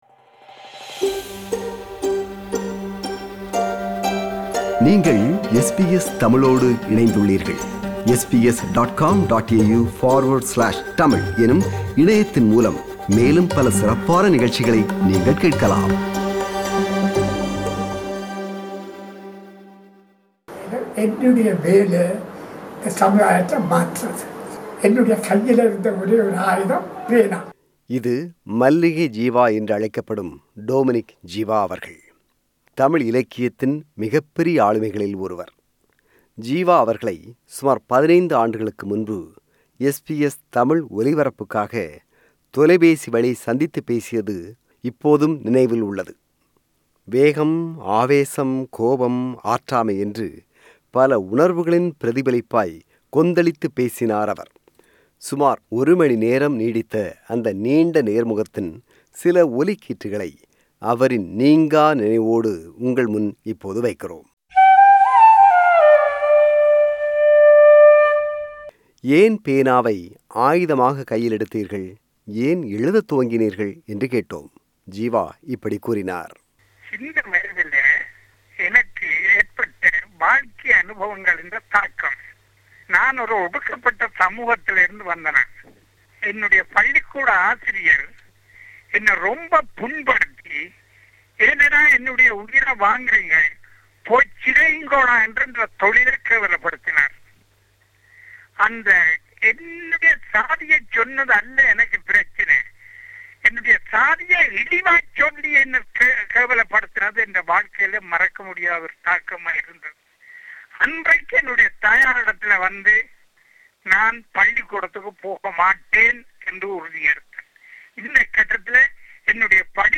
அவர் SBS தமிழ் ஒலிபரப்பிற்கு 2006 ஆம் ஆண்டு வழங்கிய நேர்முகத்தின் முக்கிய ஒலிக்கீற்றுகள்.